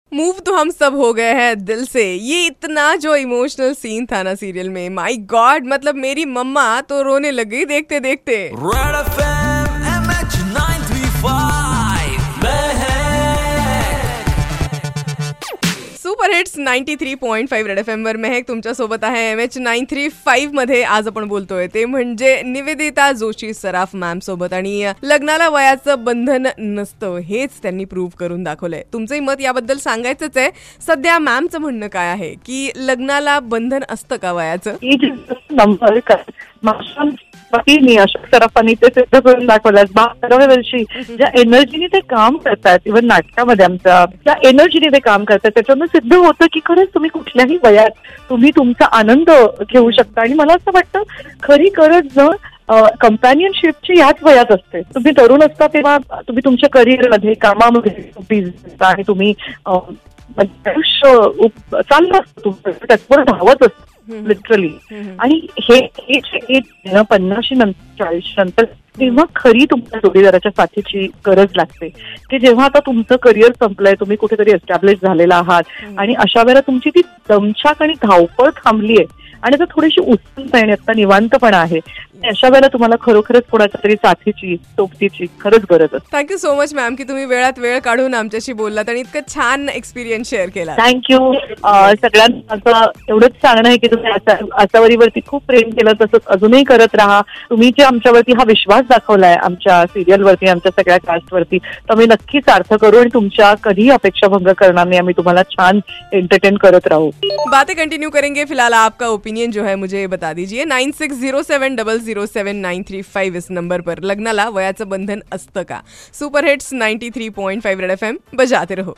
Lagnala vay asta ka: Nivedita Saraf Interview